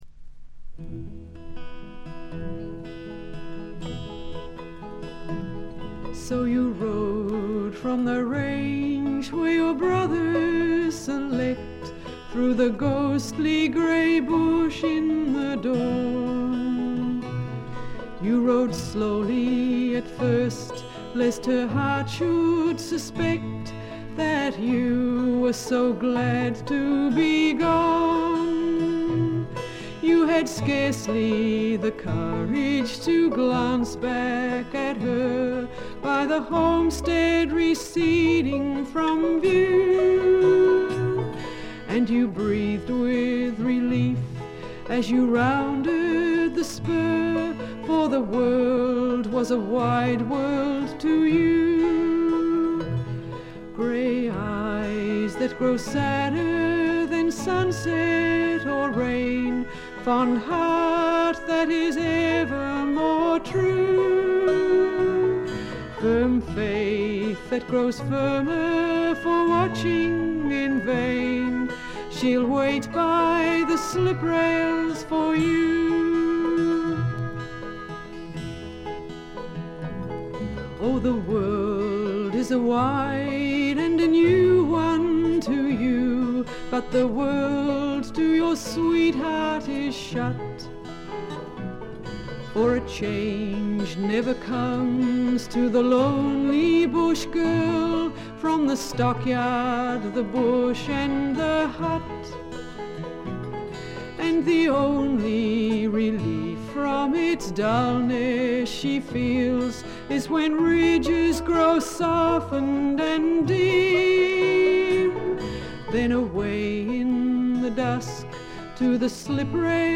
B5中盤でちょっと目立つプツ音。
試聴曲は現品からの取り込み音源です。